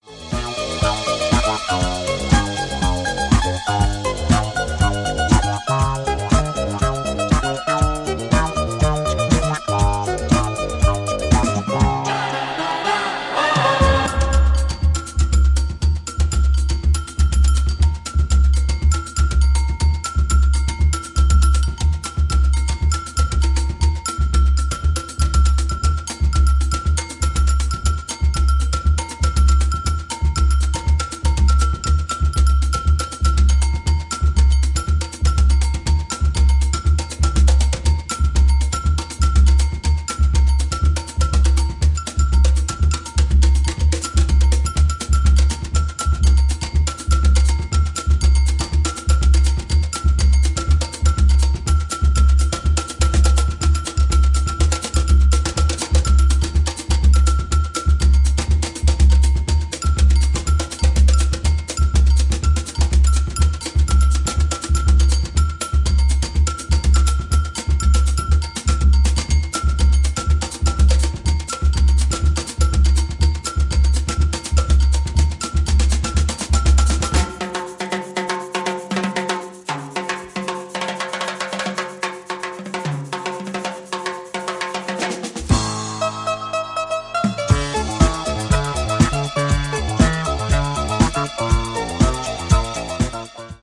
ジャンル(スタイル) DISCO / NU DISCO / RE-EDIT